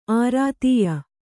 ♪ ārātīya